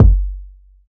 Kick (33).wav